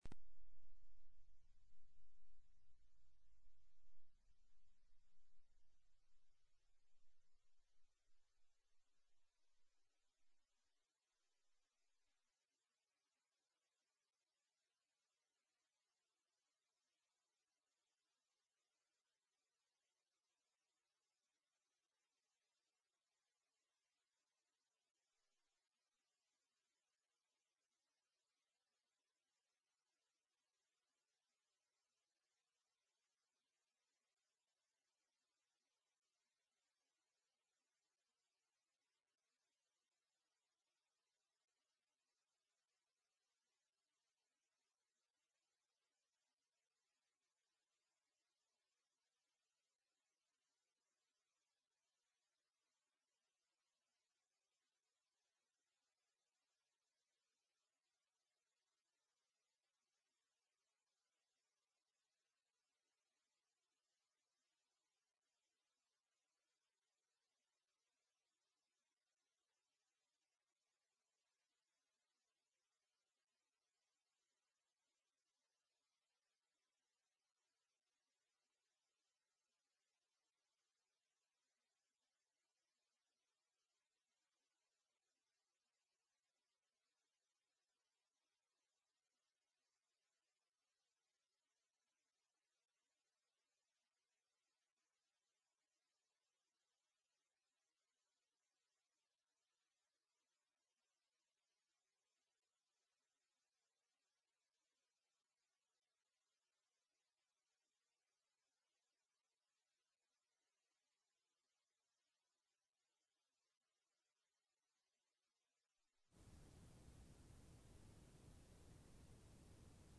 In this Sunday school lesson, we find more supplemental information concerning the law as Jesus taught it during his ministry.